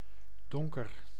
Ääntäminen
IPA: [ˈdɔŋ.kər]